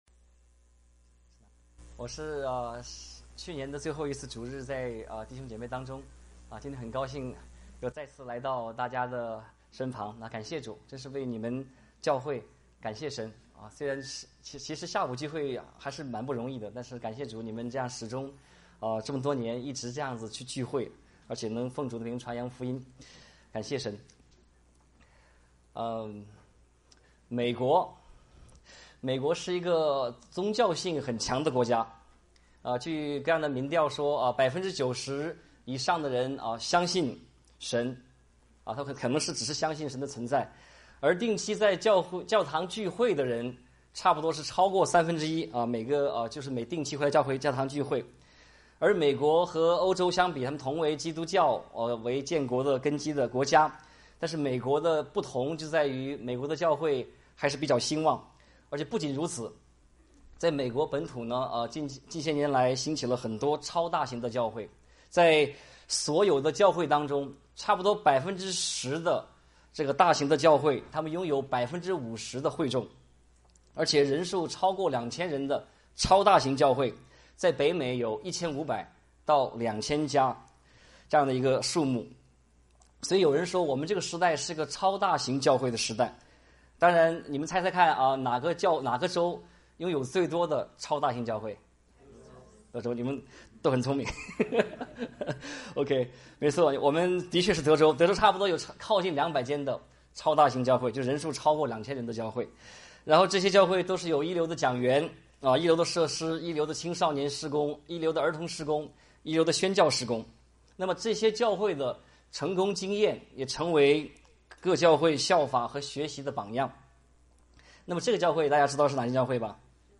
傳道 應用經文: 使徒行傳 2:41-47